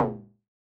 RDM_Raw_SY1-Perc02.wav